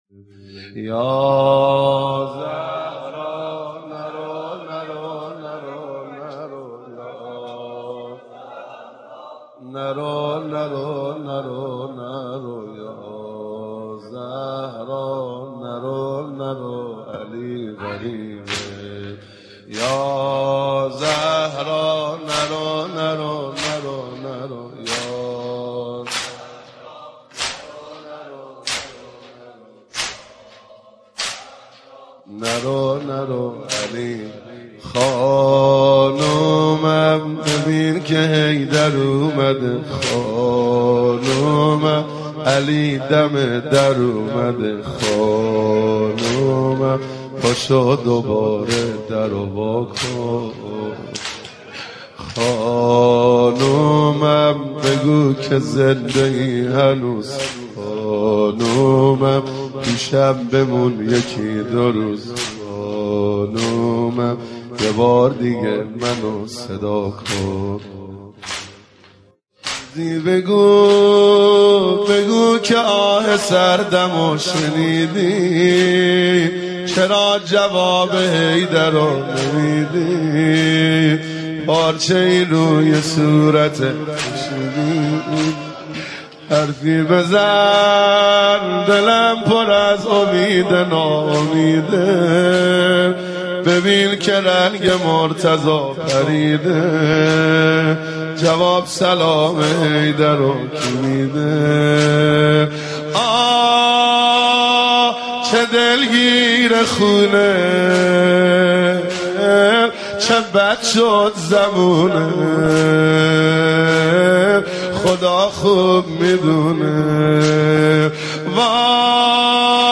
سینه زنی در شهادت مادر عاشورا، حضرت زهرا(س